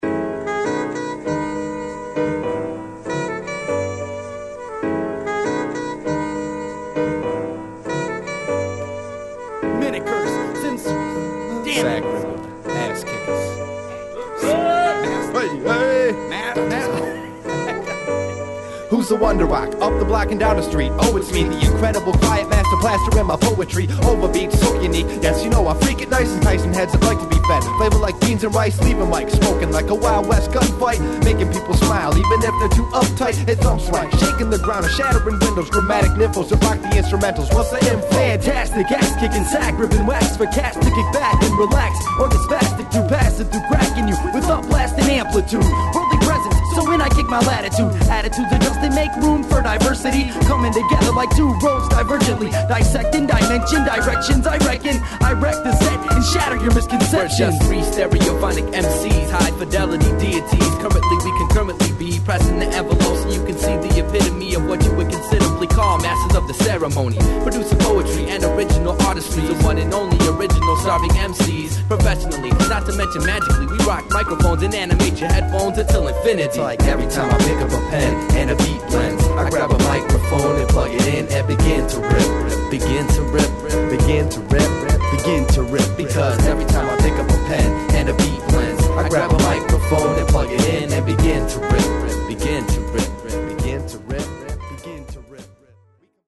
・ HIP HOP UNDERGROUND 12' & LP